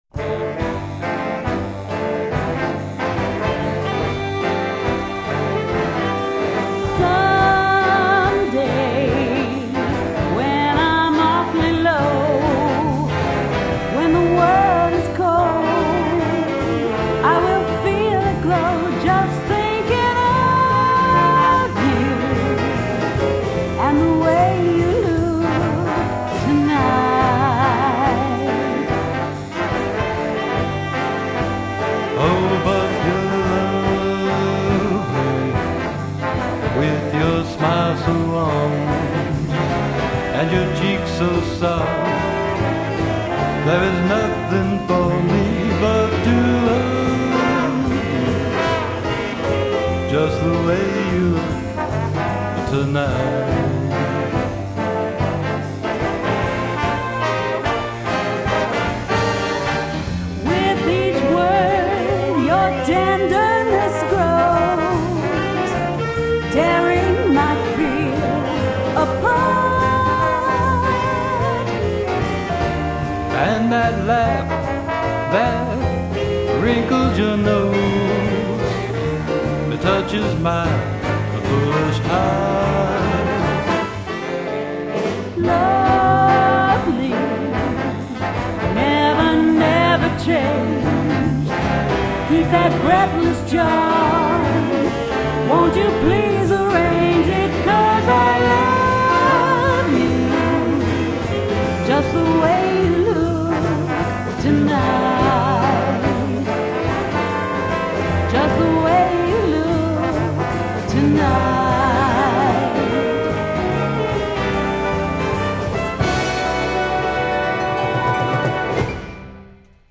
VOCALS – Classic and Contemporary